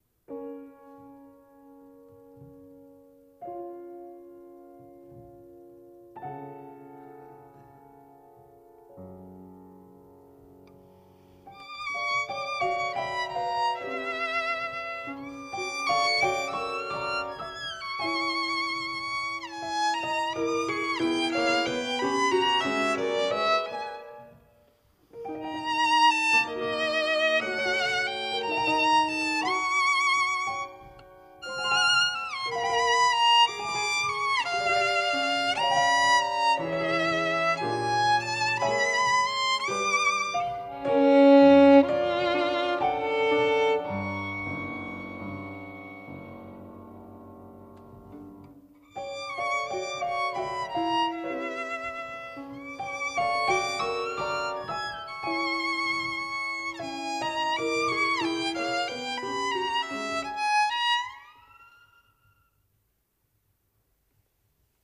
Piano
Wilton’s Music Hall 21-01-09